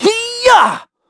Evan-Vox_Attack2.wav